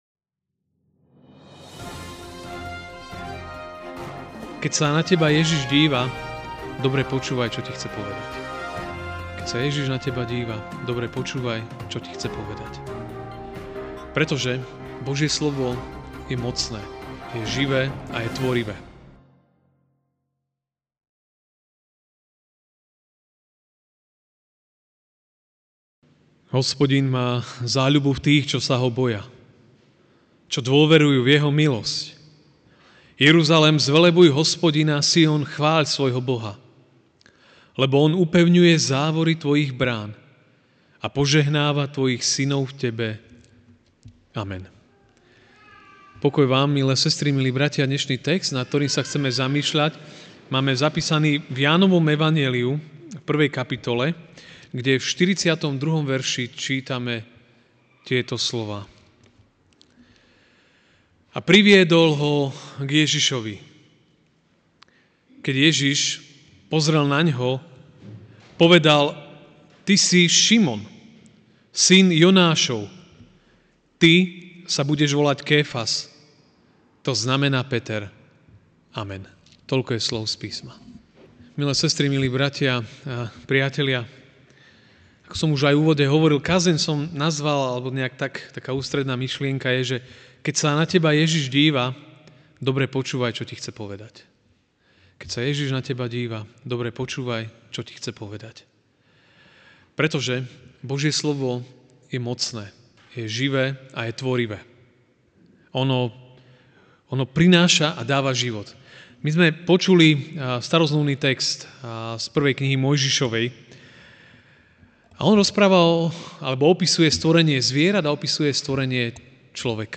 Večerná kázeň: Počúvaj slova Hospodina! ( Ján 1, 42)